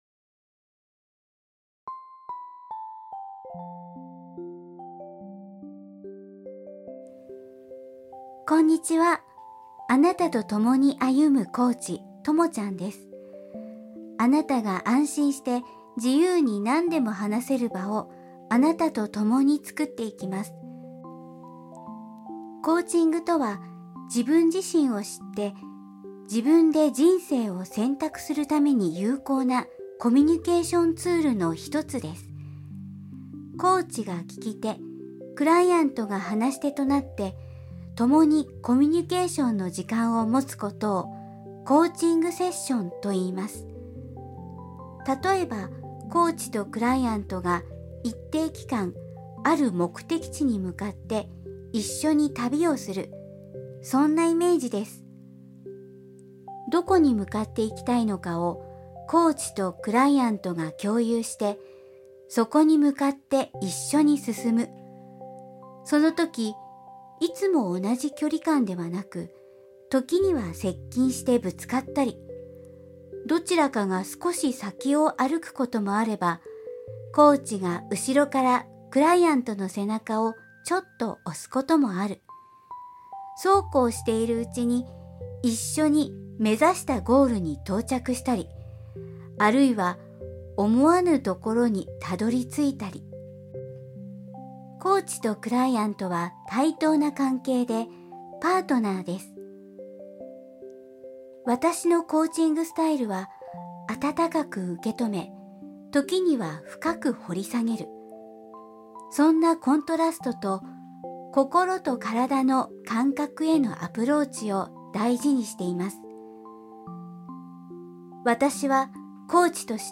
コーチからのメッセージ